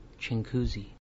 Chinguacousy Township /ɪŋˈkzi/